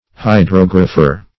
Hydrographer \Hy*drog"ra*pher\, n.
hydrographer.mp3